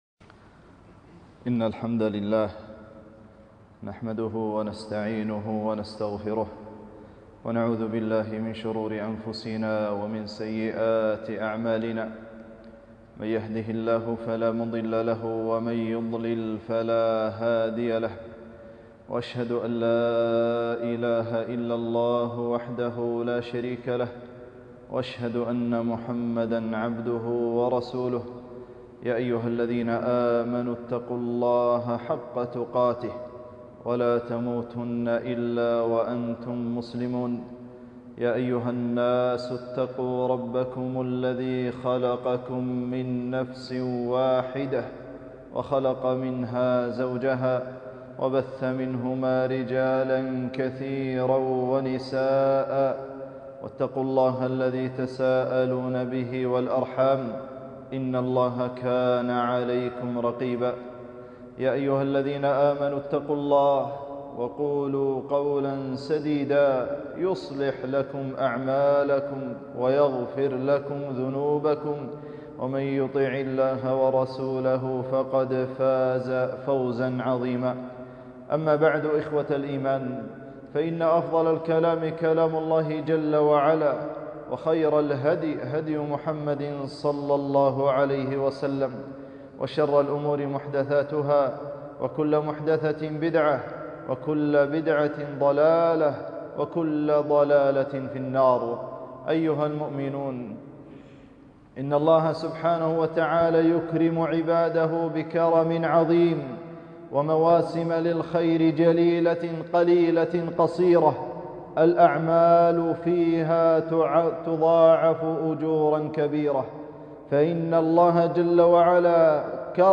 خطبة - أعمال عشر ذي الحجة وأهمية الأضحية وتعليم الأبناء لها